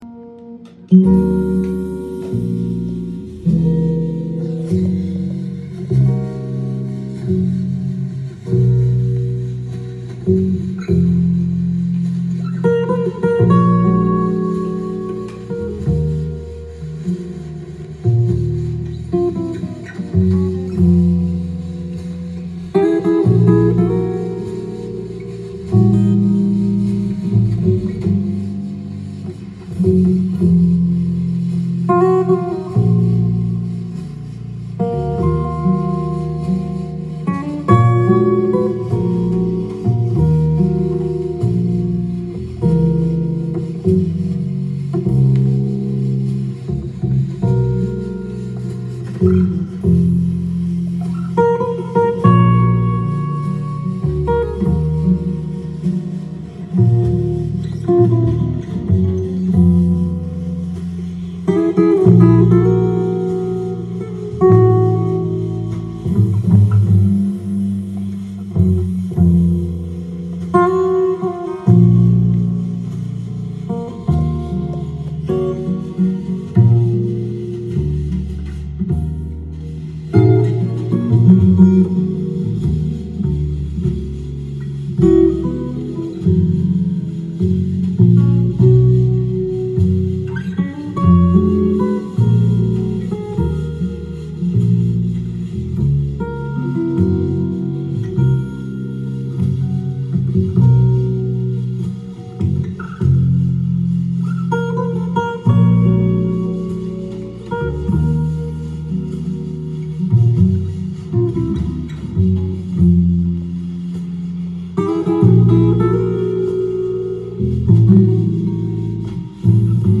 ジャンル：FUSION
店頭で録音した音源の為、多少の外部音や音質の悪さはございますが、サンプルとしてご視聴ください。
音が稀にチリ・プツ出る程度